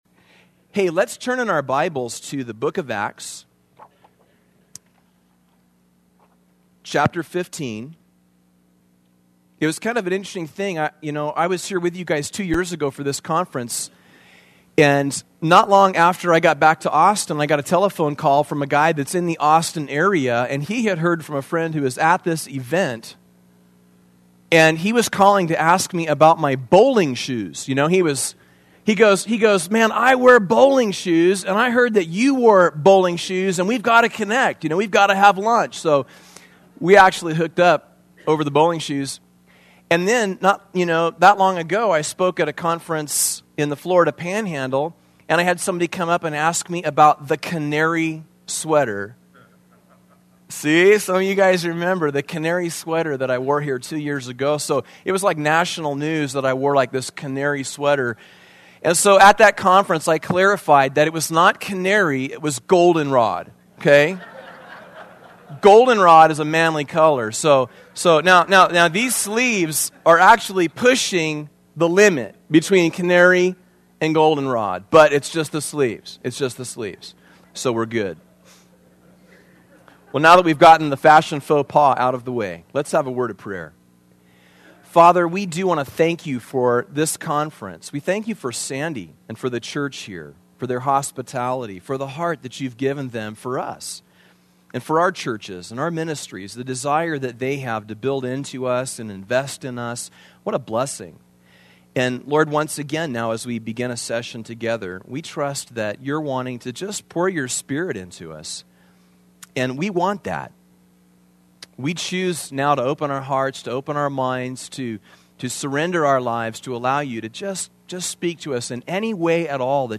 2005 Home » Sermons » Session 2 Share Facebook Twitter LinkedIn Email Topics